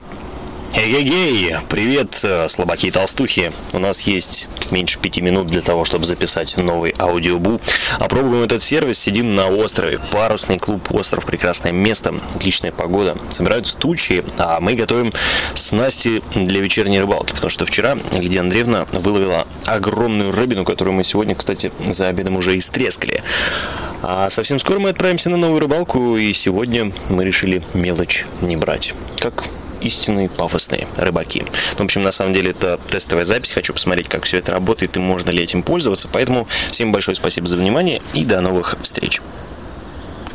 Пробная запись с Острова на Иваньковском водохранилище.